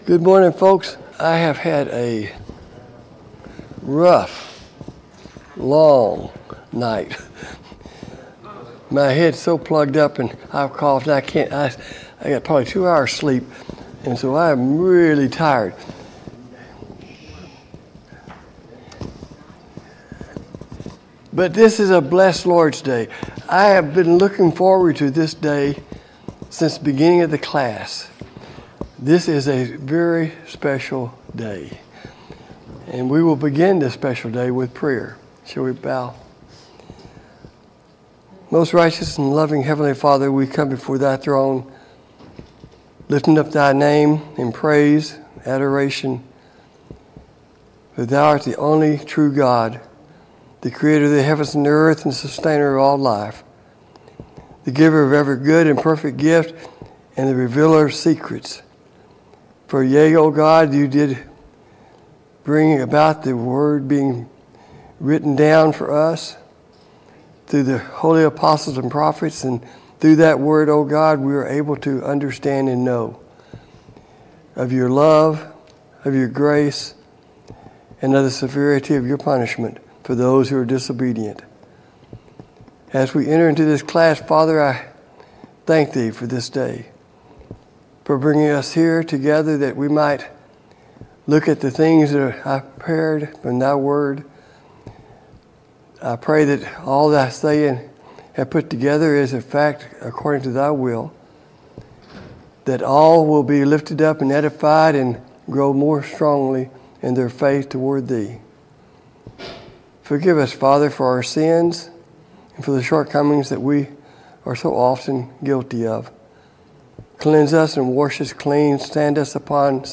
God's Scheme of Redemption Service Type: Sunday Morning Bible Class « Study of Paul’s Minor Epistles